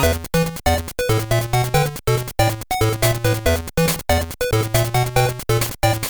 short extract of the first stage's music plays during this.